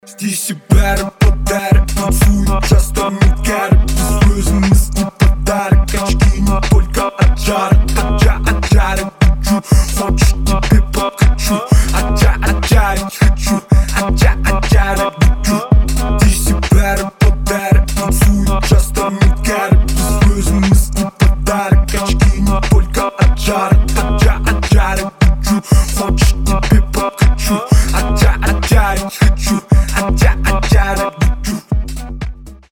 • Качество: 320, Stereo
клубняк